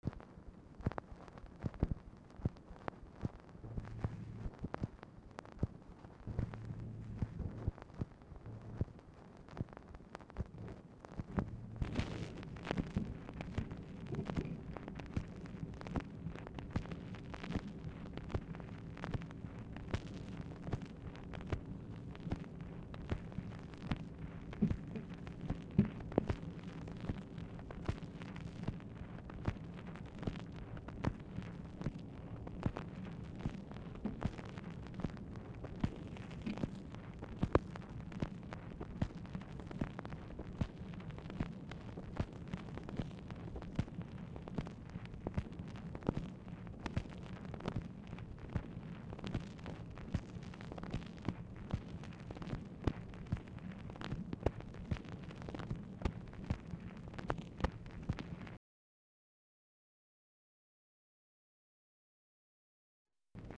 Telephone conversation # 5118, sound recording, MACHINE NOISE, 8/22/1964, time unknown | Discover LBJ
Format Dictation belt
Specific Item Type Telephone conversation